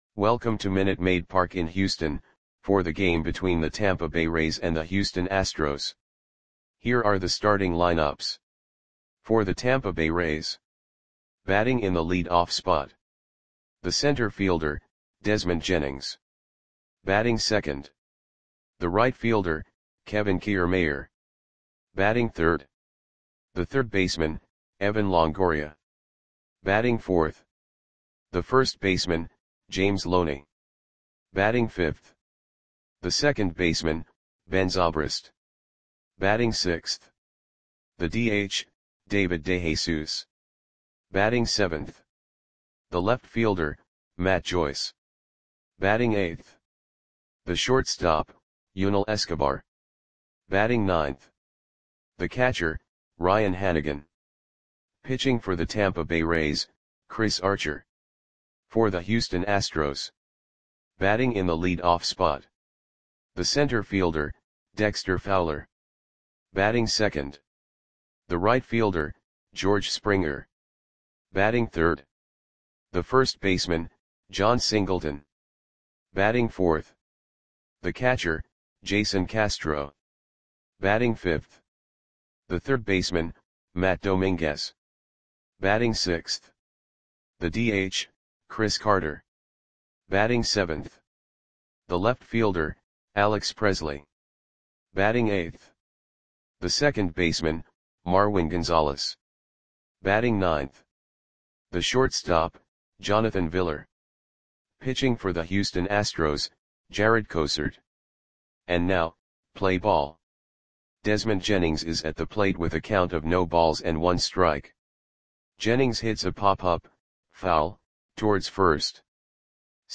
Audio Play-by-Play for Houston Astros on June 14, 2014
Click the button below to listen to the audio play-by-play.